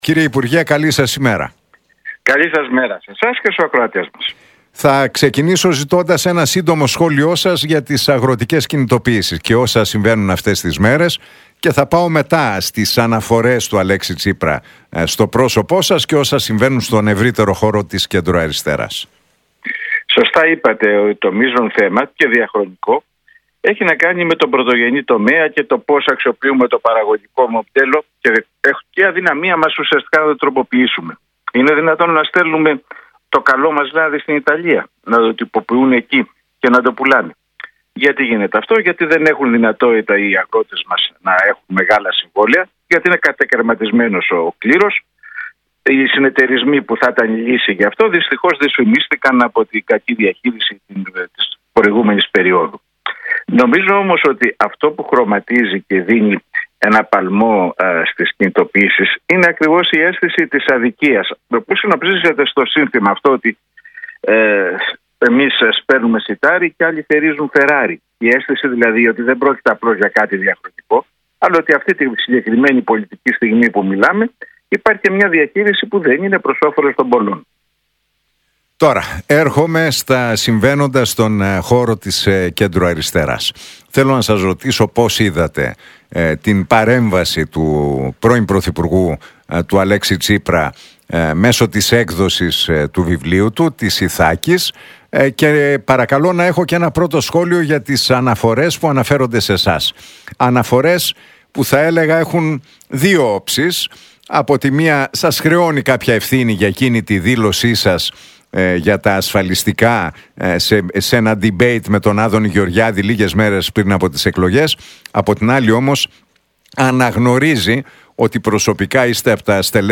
Για τις αγροτικές κινητοποιήσεις, την ανασύνταξη του προοδευτικού χώρου και το βιβλίο του Αλέξη Τσίπρα μίλησε ο συνταγματολόγος και πρώην υπουργός, Γιώργος Κατρούγκαλος στον Νίκο Χατζηνικολάου από τη συχνότητα του Realfm 97,8.